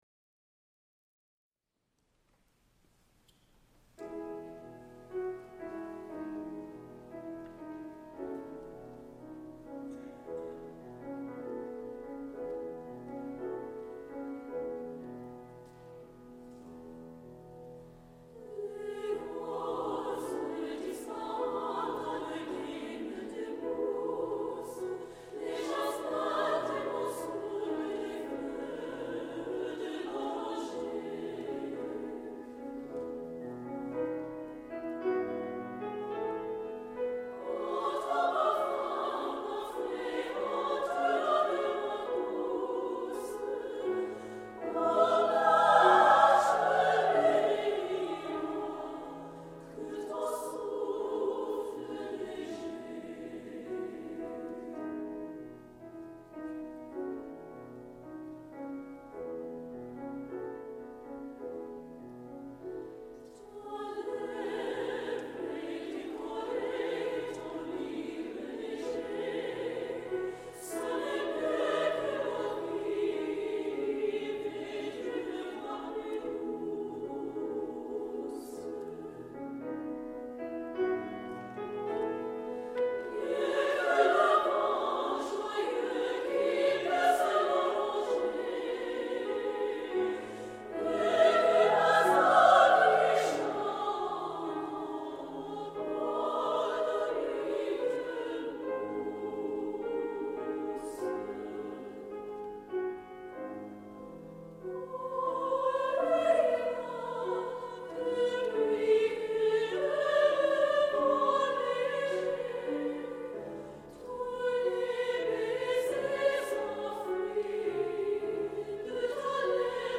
Accompaniment:      With Piano
Music Category:      Choral
for treble voices with lush sonority and romantic appeal